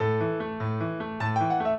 piano
minuet10-6.wav